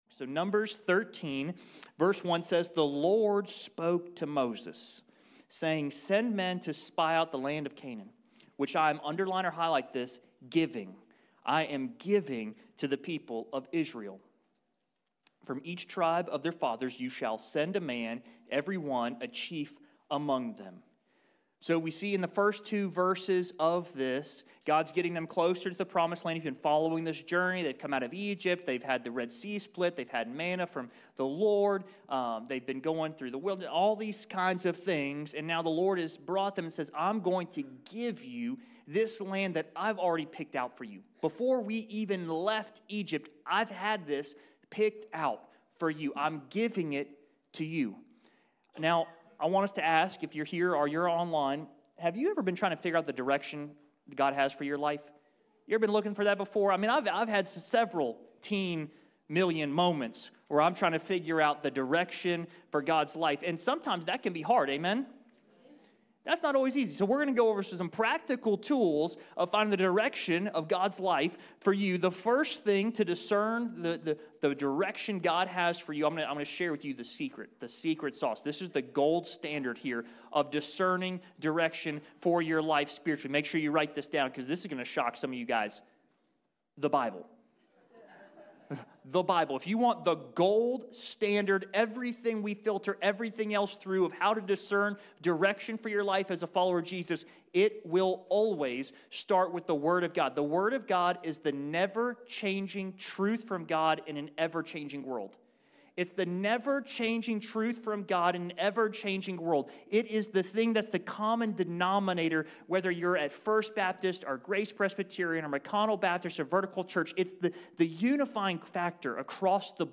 Here's a message for you to watch at home on your snow day!